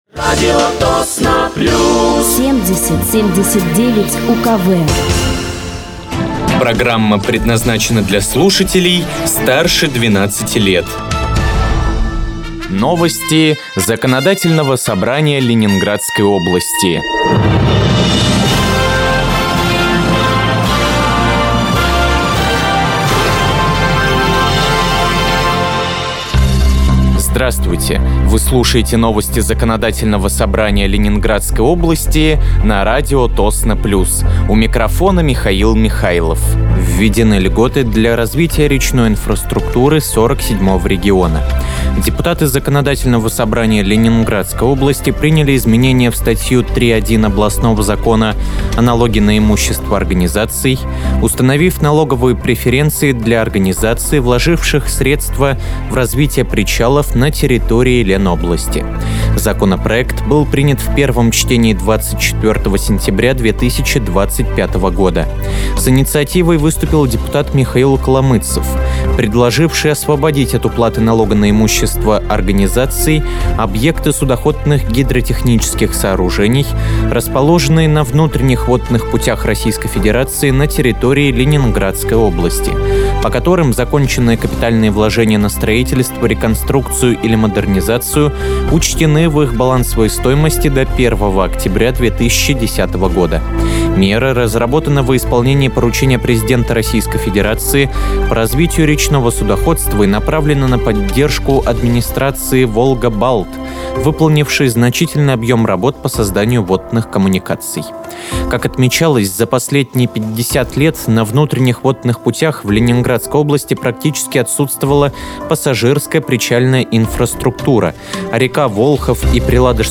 Выпуск новостей Законодательного собрания Ленинградской области от 18.11.2025
Вы слушаете новости Законодательного собрания Ленинградской области на радиоканале «Радио Тосно плюс».